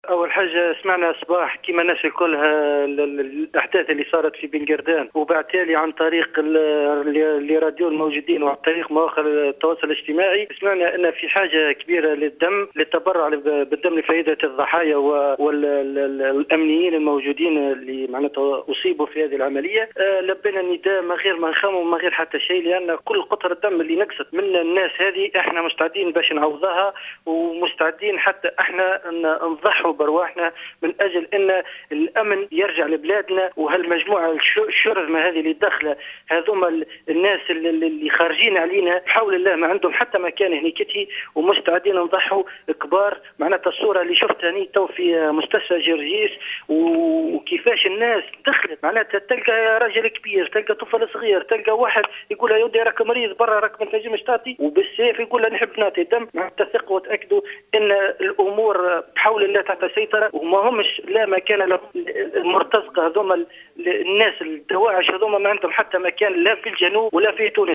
وأفاد أحد المواطنين في تصريح لمراسل الجوهرة أف أم أنهم لبوا هذا النداء وقدموا على جناح السرعة من كل حدب وصوب للتبرع بالدم مضيفا أنهم مستعدون لفداء تونس بدمائهم وأنه لا مكان لشرذمة المرتزقة الارهابيين في بلادنا.